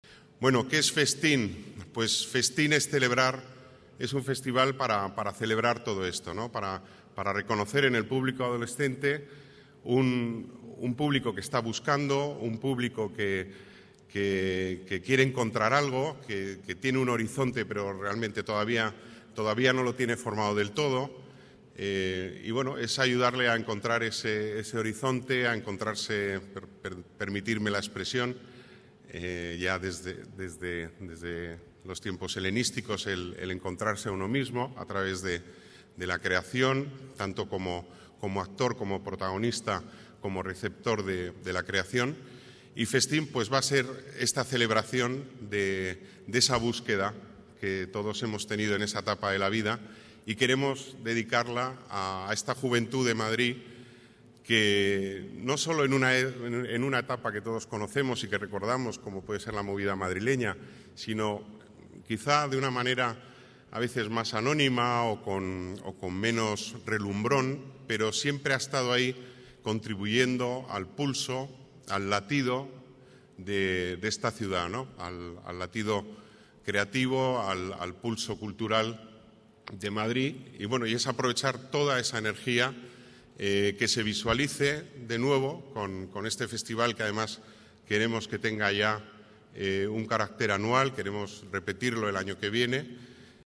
Nueva ventana:Declaraciones delegado Las Artes, Pedro Corral: primer FESTenn<18 en Matadero Madrid